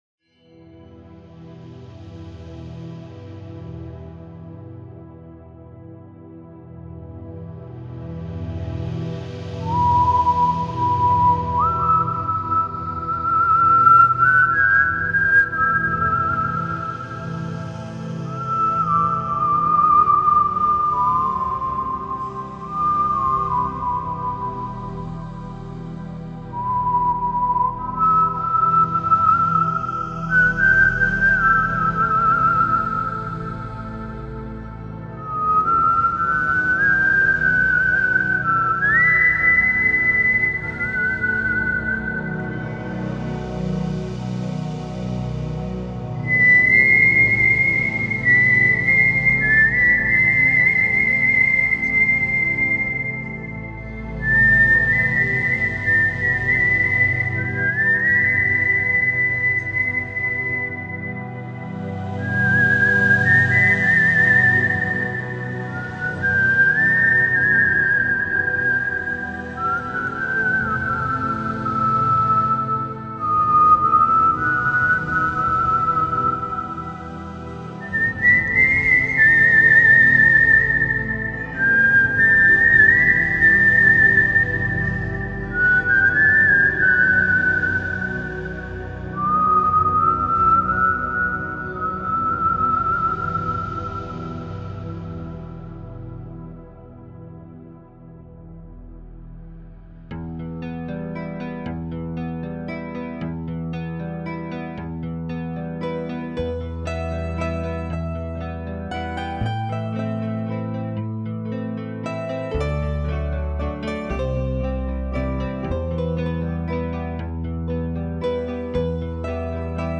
Instrumental (MP3 - 2 min 56 secs - 2.69 Mb)